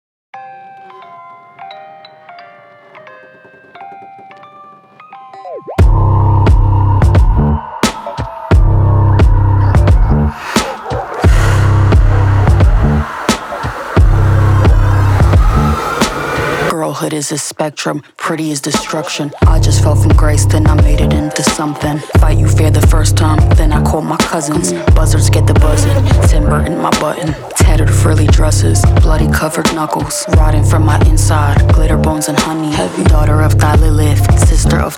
Жанр: Хип-Хоп / Рэп / Поп музыка
Hip-Hop, Rap, Pop